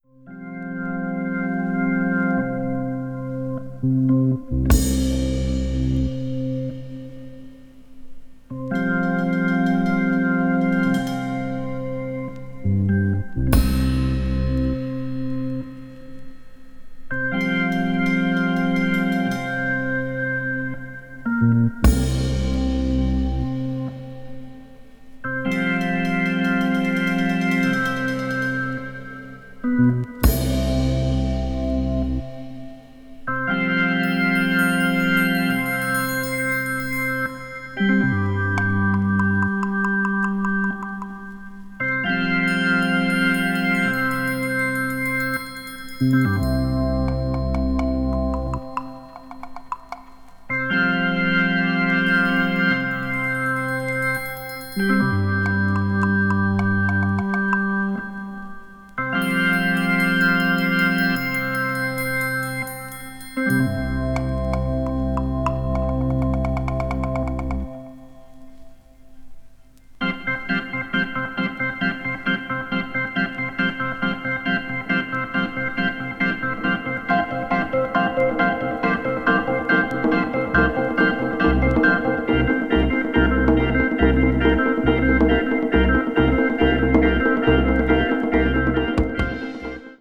media : EX/EX(わずかにチリノイズが入る箇所あり)